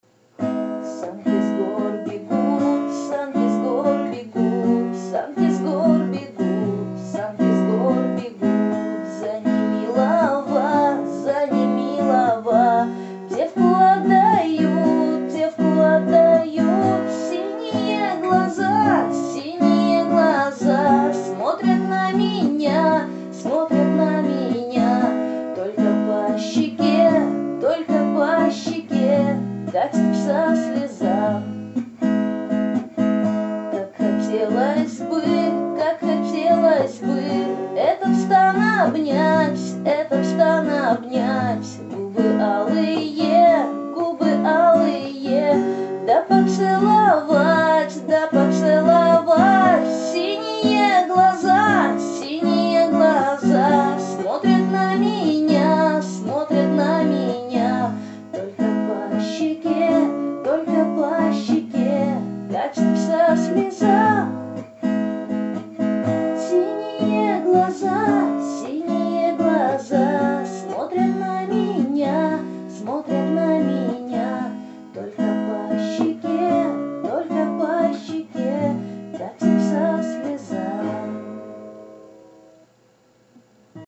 Лирические